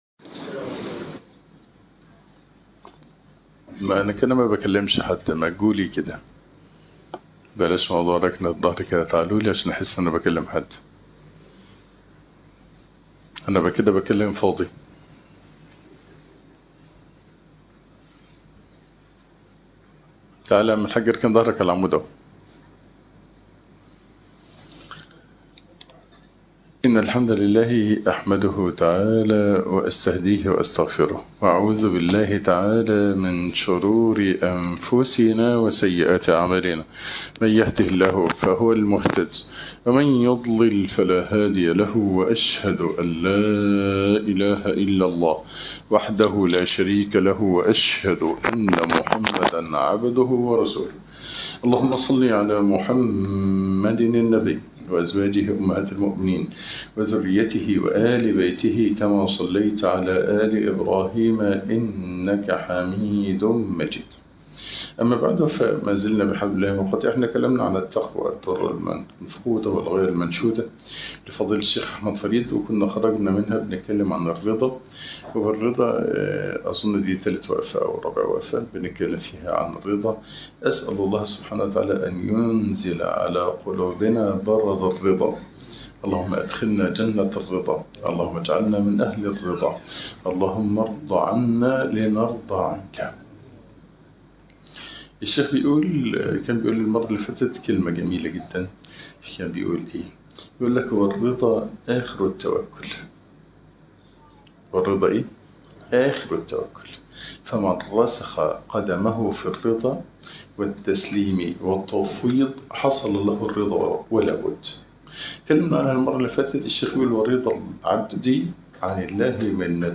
فقه الرضا ) الدرس الثالث